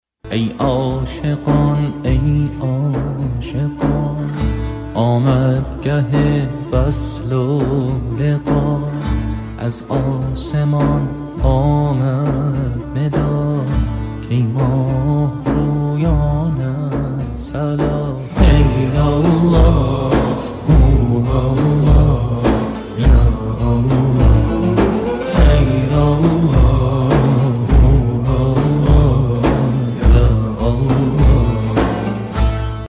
زنگ خور موبایل (با کلام) ملایم